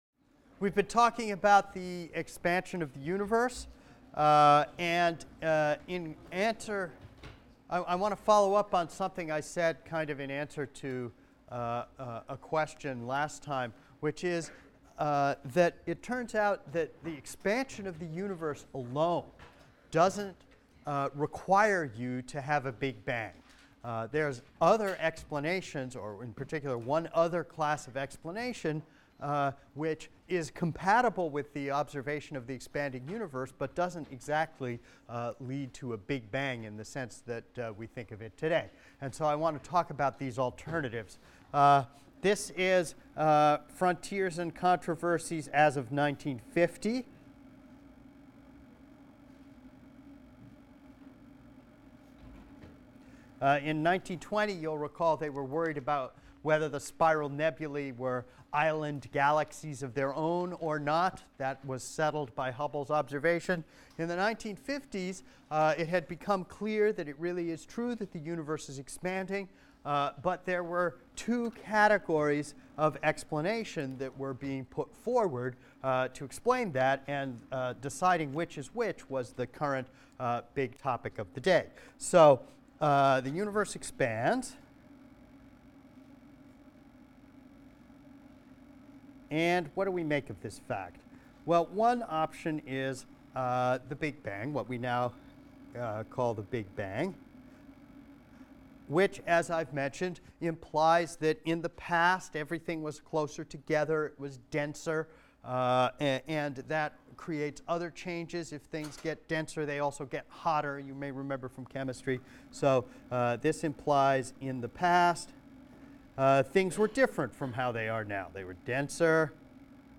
ASTR 160 - Lecture 18 - Hubble’s Law and the Big Bang (cont.) | Open Yale Courses